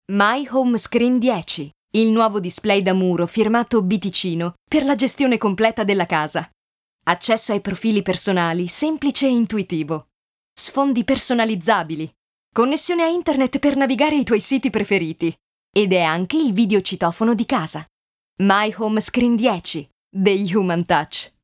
Frau
Italian speaker-vocal range 5-40 years.Vital,natural and mellow tone of voice.Voice over for Samsung,Volkswagen,Suzuki,Nintendo
Sprechprobe: Werbung (Muttersprache):
My voice is vital, natural, lively and mellow and the vocal range is 5-40 years.